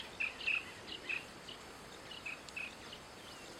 Playerito Pectoral (Calidris melanotos)
Nombre en inglés: Pectoral Sandpiper
Condición: Silvestre
Certeza: Vocalización Grabada